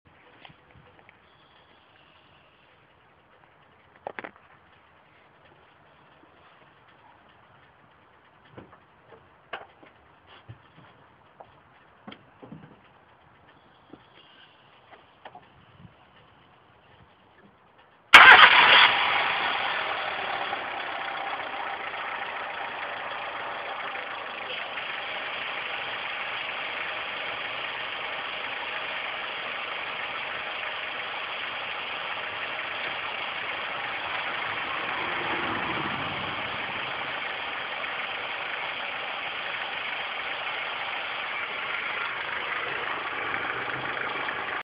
Hab auch das problem das wenn ich im leerlauf bin & die kupplung nicht trette dieses rauschen kommt ich hab das mal aufgenommen klingt das bei dir auch so ?
Es ist sehr Leise . daher bitte erst ab ca. 20sec. aufdrehen bis man was hört sonst fallen euch vorher beim starten des motors die ohren ab ;) . ab 24+ sec. fängt das rauschen an . hab beim starten die kupplung getretten.
Motor start .
Kupplung getreten.
Kupplung los gelassen & leer lauf.